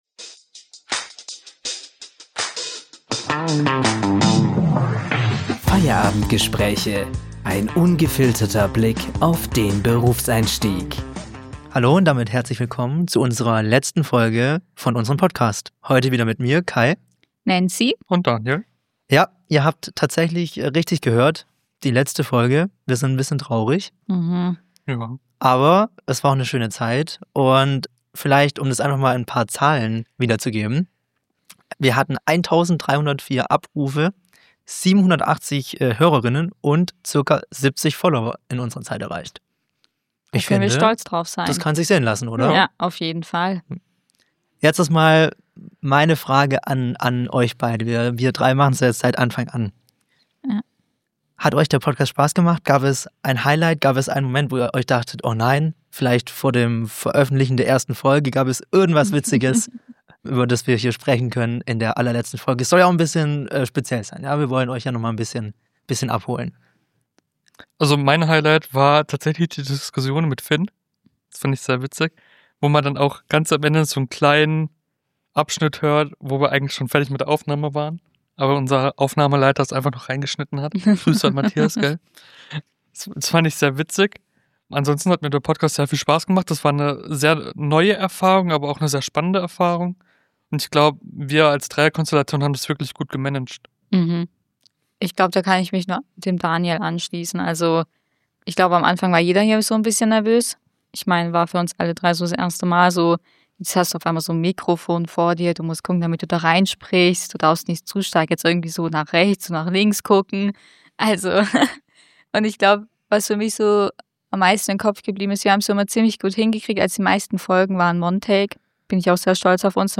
Denn beinahe hätten wir den wichtigsten Teil fast vergessen und mussten nochmal ans Mikro.